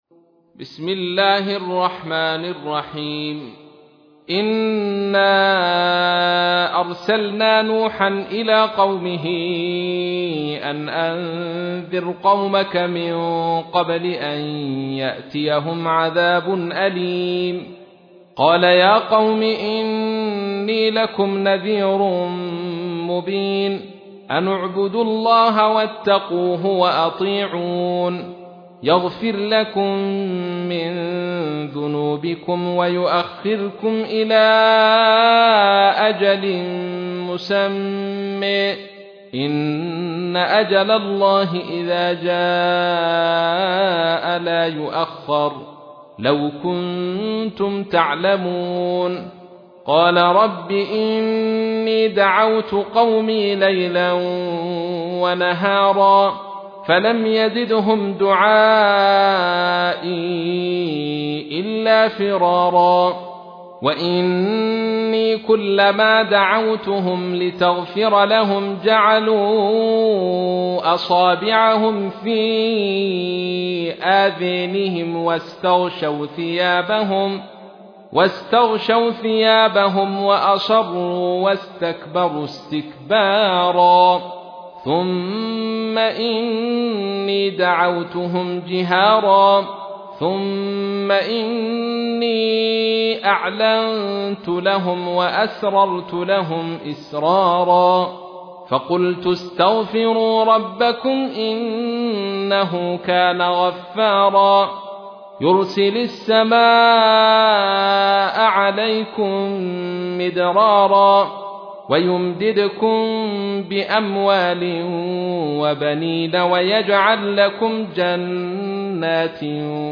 تحميل : 71. سورة نوح / القارئ عبد الرشيد صوفي / القرآن الكريم / موقع يا حسين